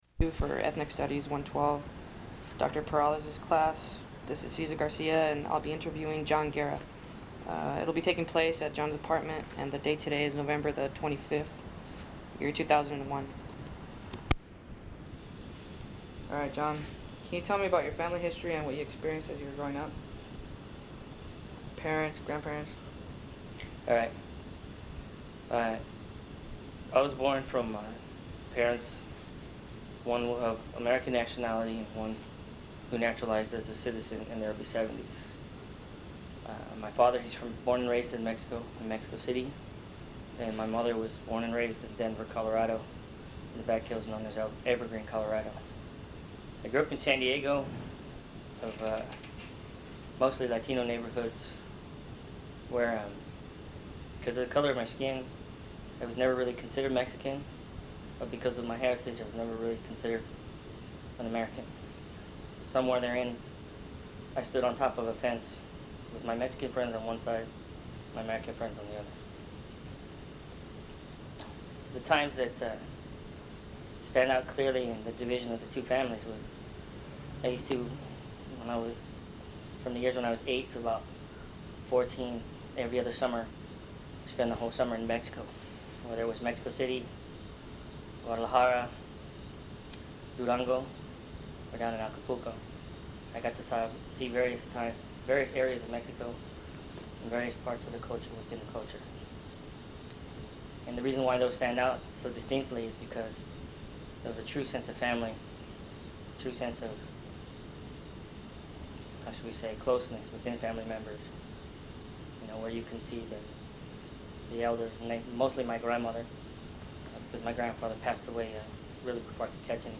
Interview
Form of original Audiocassette